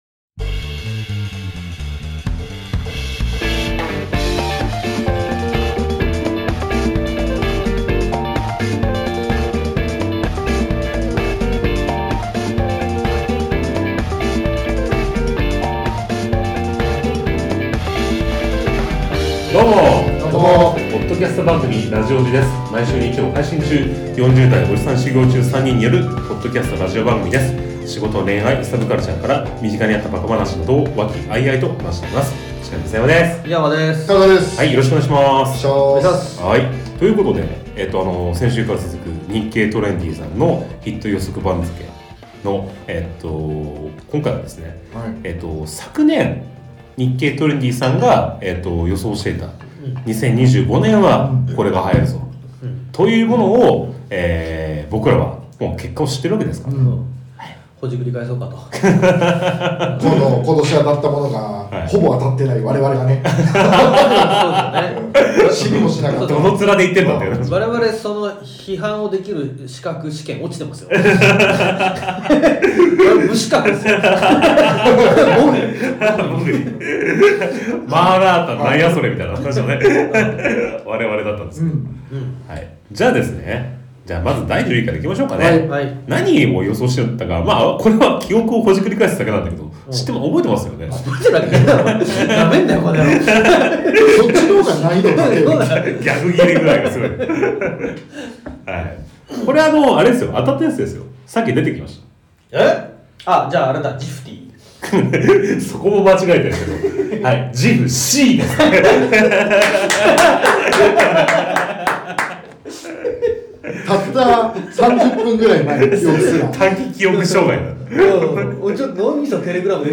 30代オジサン初心者3人によるポッドキャストラジオ。仕事、恋愛、サブカルから身近にあった馬鹿話等を和気あいあいと話しています。